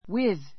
wið